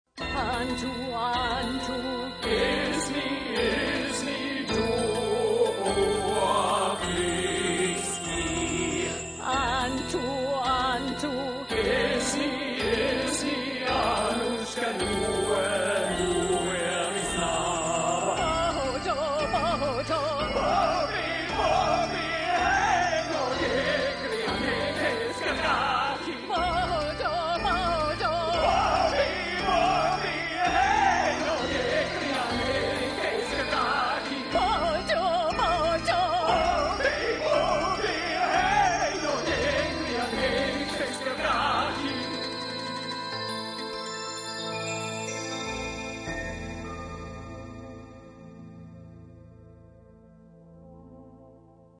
Voice still used operatically but magnified in presence.